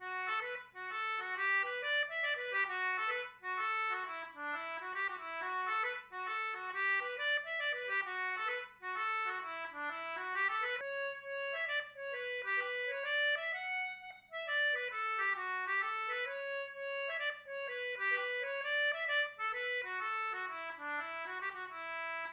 Most Celtic tunes were written for a specific instrument, such as the harp, flute, whistle, etc. The audio clips on this web site were played using English concertinas.
Snowy Path Key D - Slip Jig